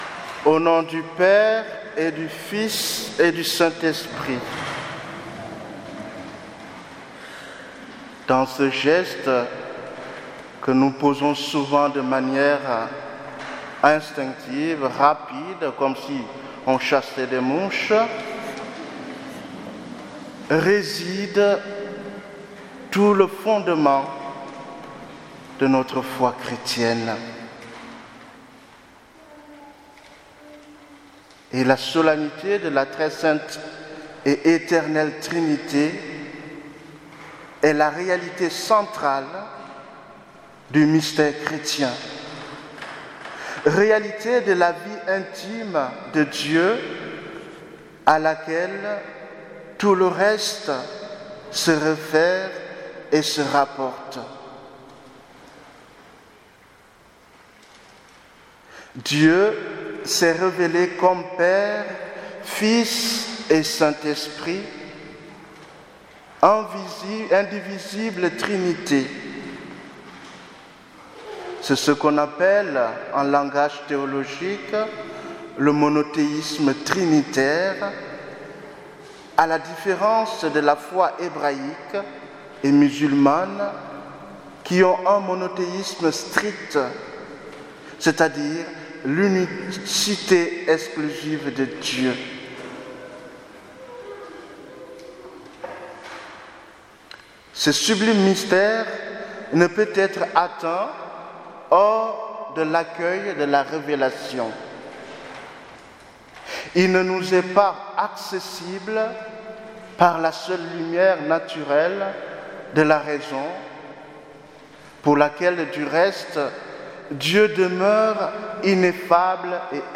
Homélie du dimanche de la Sainte Trinité 2018